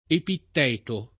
[ epitt $ to ]